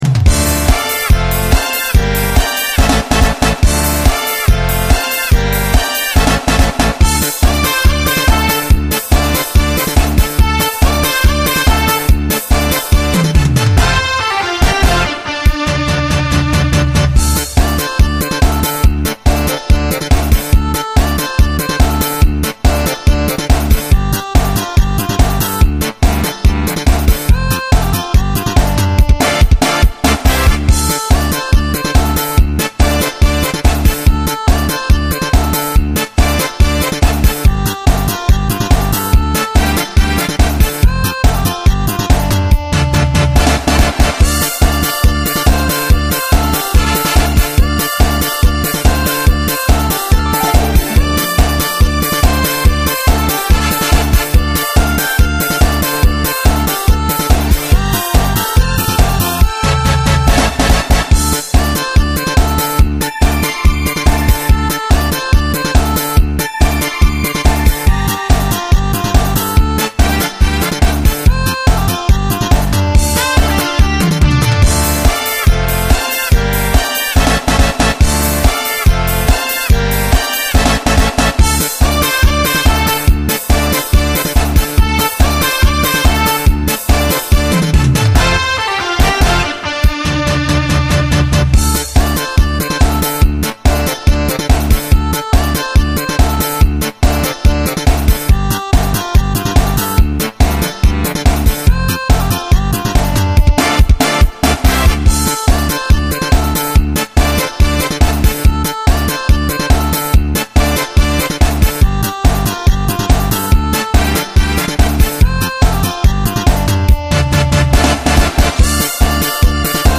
디스코